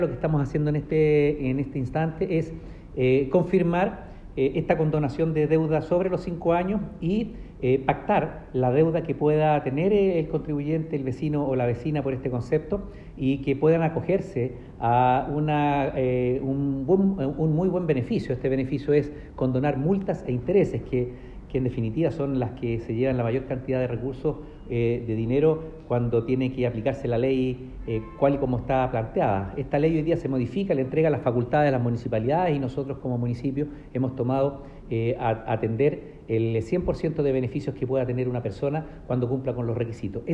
alcalde-condonacion-deudas-de-aseo-1.m4a